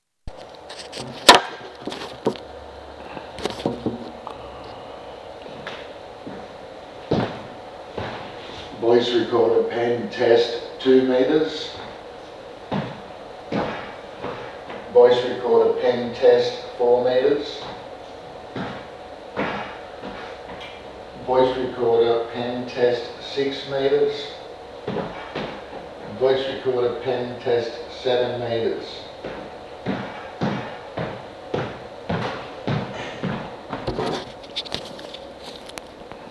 ESONIC Voice Recorder Pen with OLED Screen
AFOPCM008-ESONIC-Quality-Voice-Recorder-Pen-with-OLED-Screen-Sample-Audio.wav